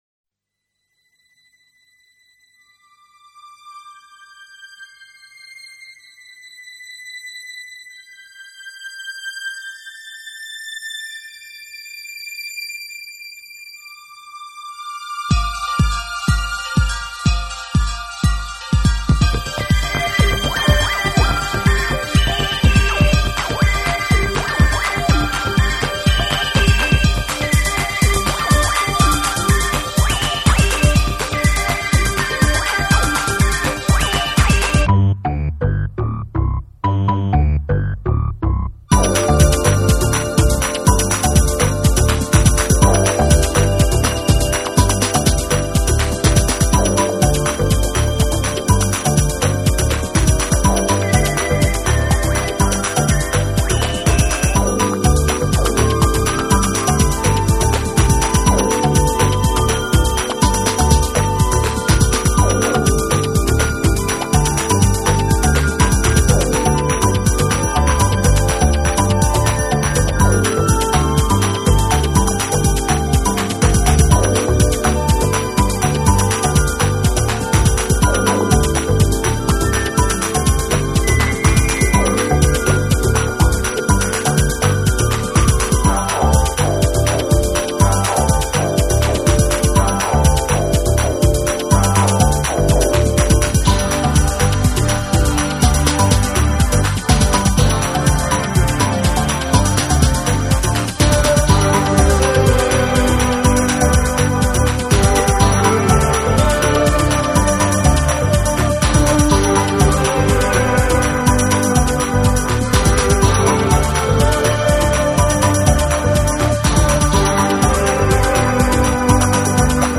TECHNO & HOUSE / CD